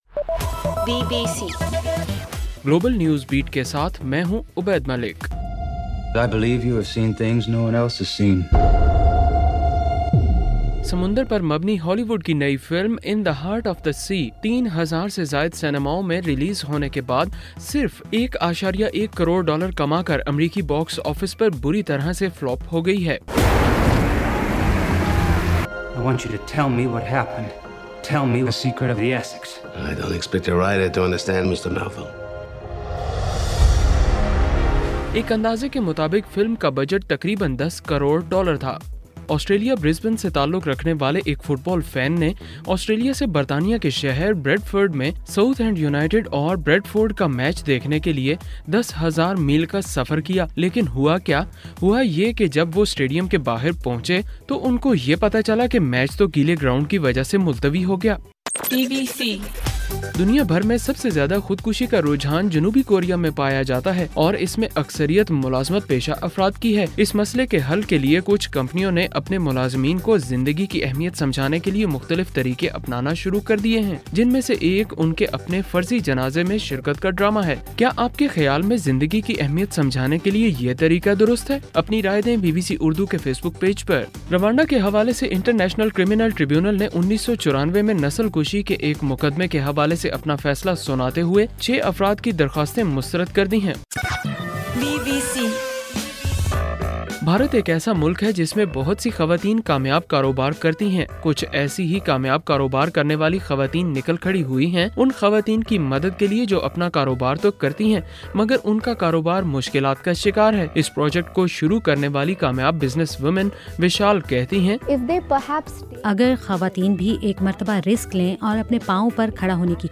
دسمبر 14: رات 12 بجے کا گلوبل نیوز بیٹ بُلیٹن